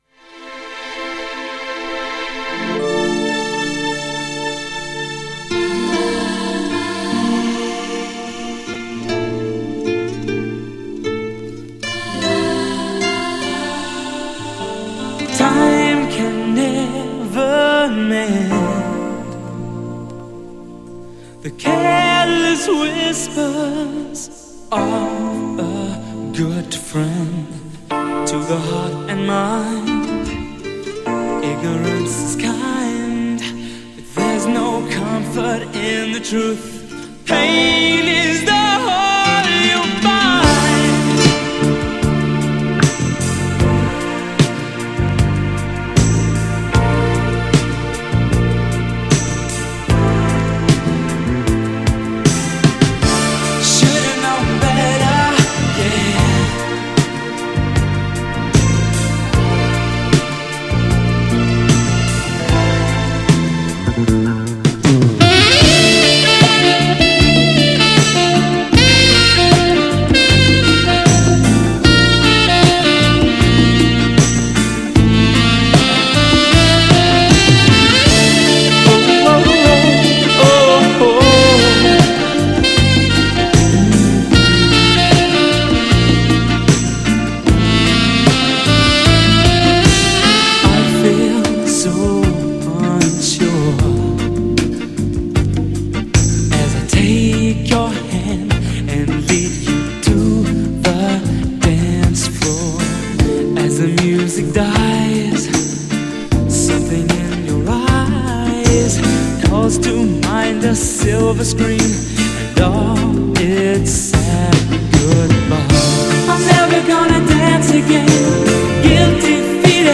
メロウ＆アーバン!!
GENRE Dance Classic
BPM 76〜80BPM